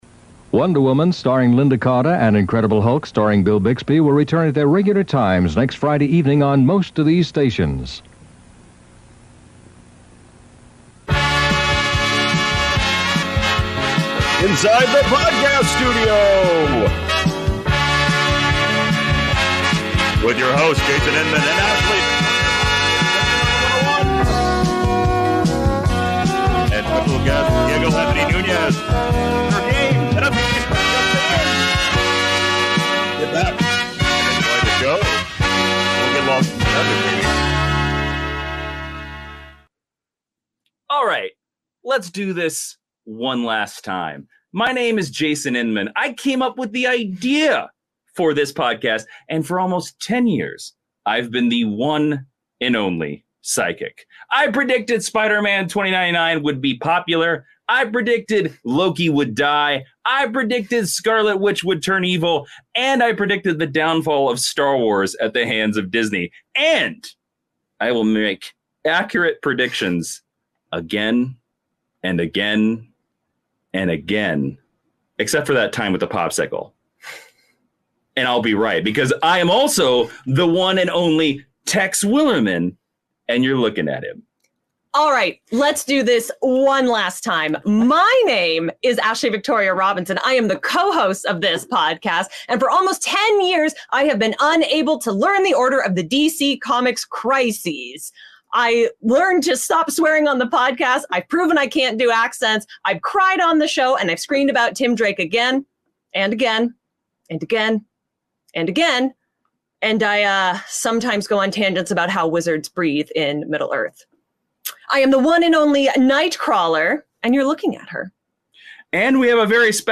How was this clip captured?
That's LIVE!